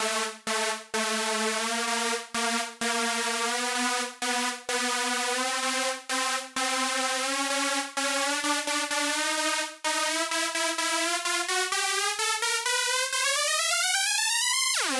描述：用Dune2制作的EDM房屋和弦
标签： 128 bpm House Loops Synth Loops 2.53 MB wav Key : C
声道立体声